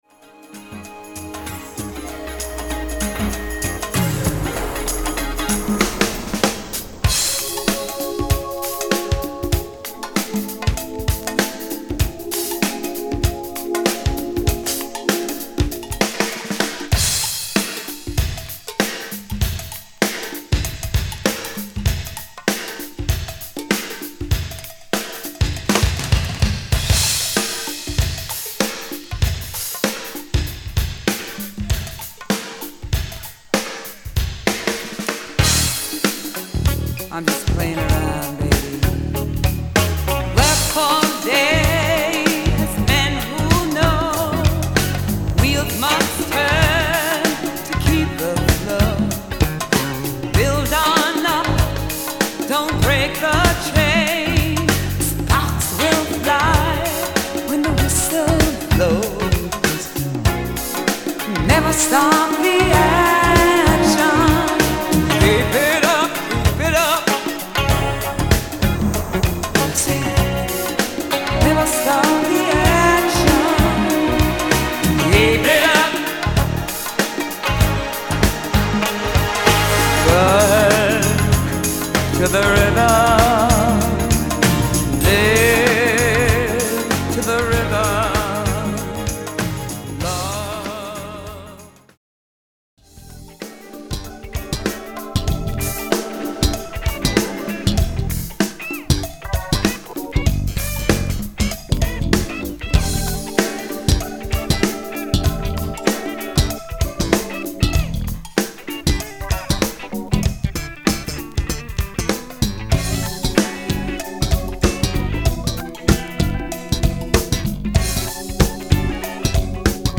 DISCO
チリチリしてます。